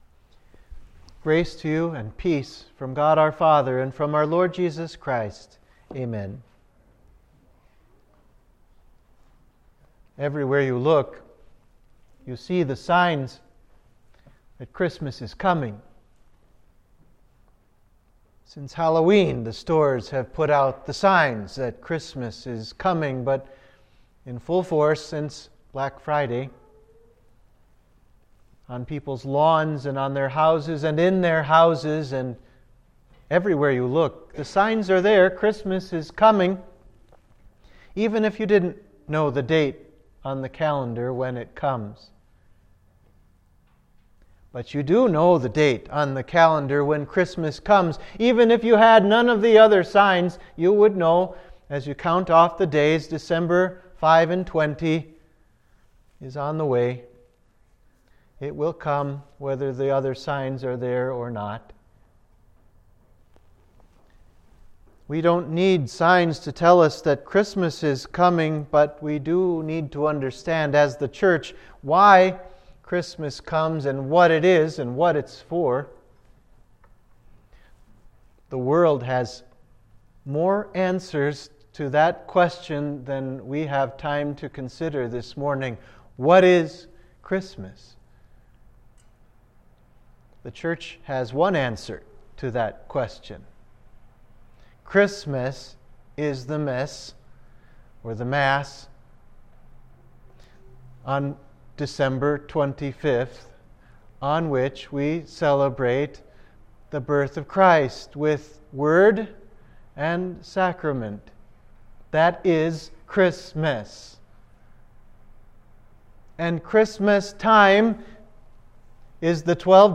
Sermon forPopulus Zion- Advent 2